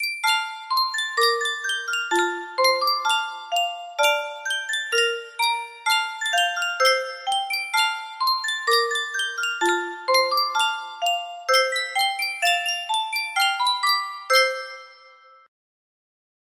Sankyo Music Box - Jeanie With the Light Brown Hair CAH music box melody
Full range 60